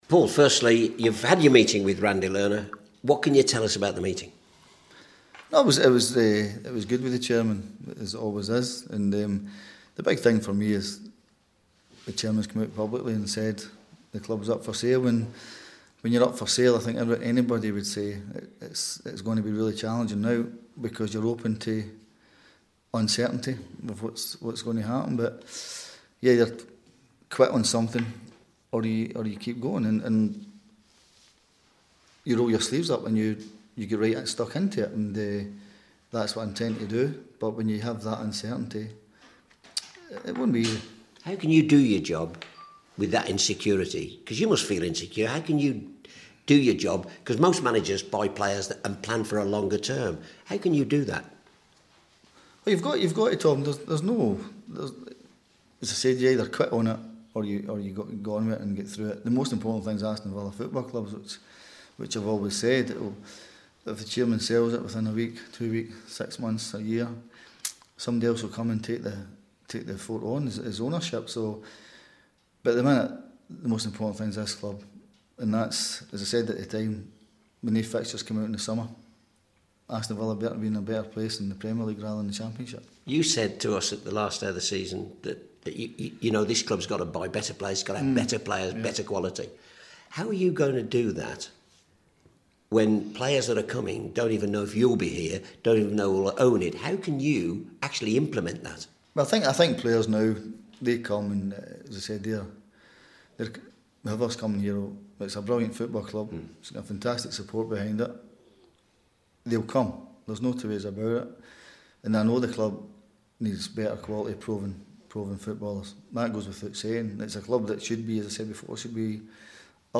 Paul Lambert speaks to the media after returning from talks with Villa owner Randy Lerner...